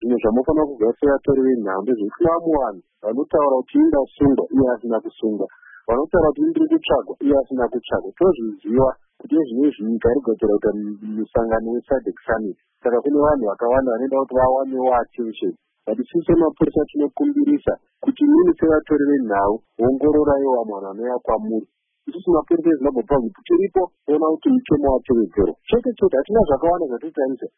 Mashoko aCommissioner Paul Nyathi